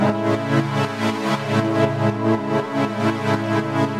Index of /musicradar/sidechained-samples/120bpm
GnS_Pad-alesis1:8_120-A.wav